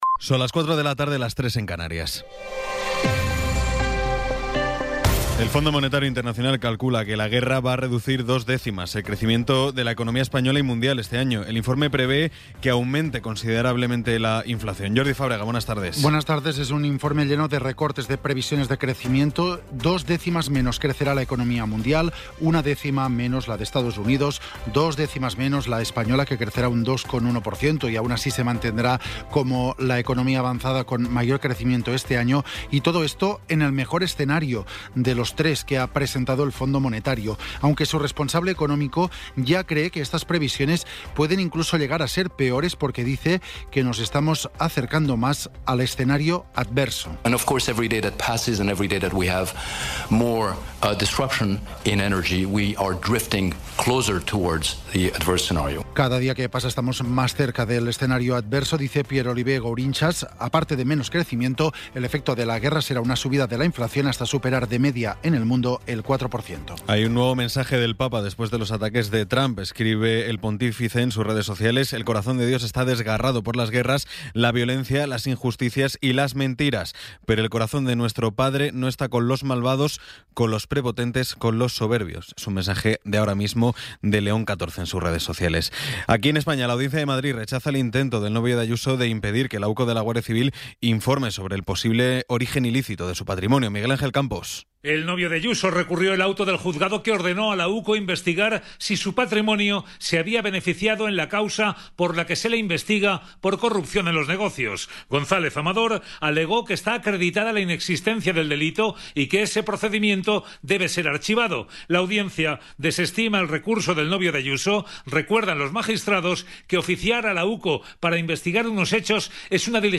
Resumen informativo con las noticias más destacadas del 14 de abril de 2026 a las cuatro de la tarde.